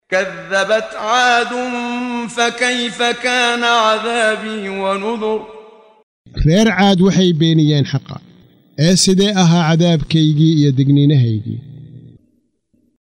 Waa Akhrin Codeed Af Soomaali ah ee Macaanida Suuradda Al-Qamar ( Dayaxa ) oo u kala Qaybsan Aayado ahaan ayna la Socoto Akhrinta Qaariga Sheekh Muxammad Siddiiq Al-Manshaawi.